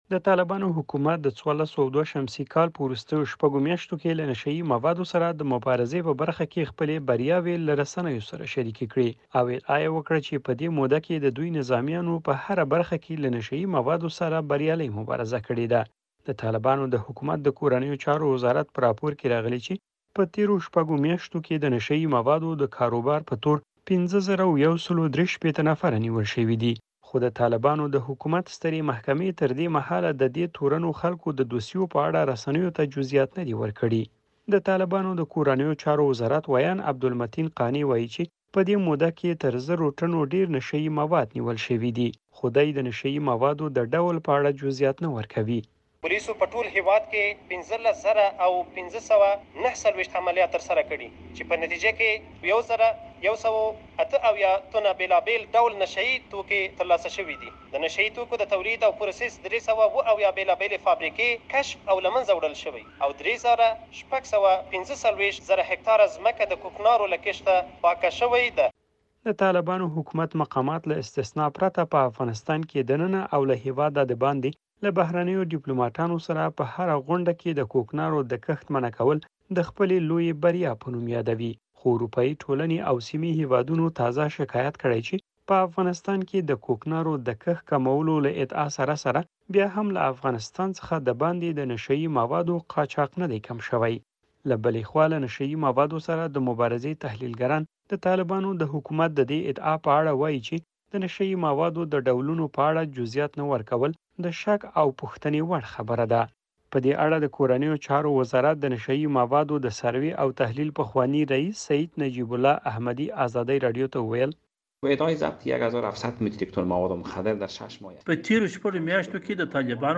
د نشه يي موادو د نيولو راپور